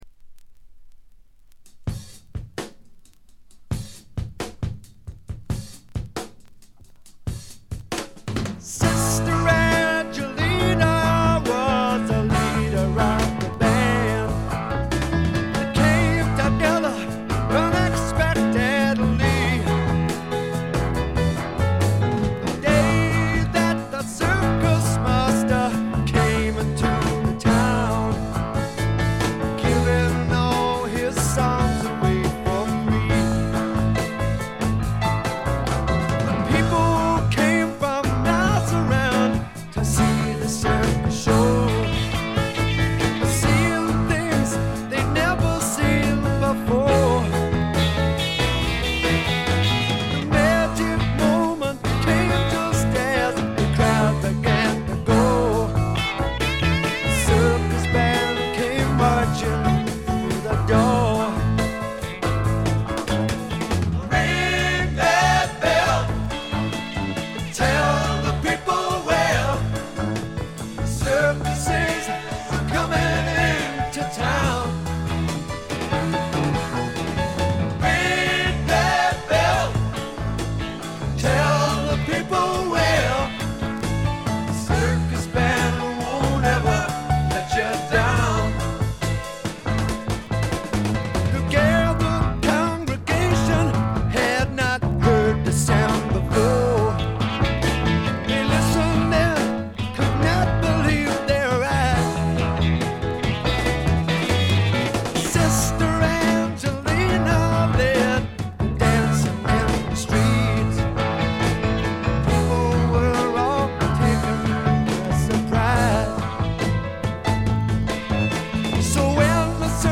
静音部でチリプチ。散発的なプツ音少し。
ちょいと鼻にかかった味わい深いヴォーカルがまた最高です。
試聴曲は現品からの取り込み音源です。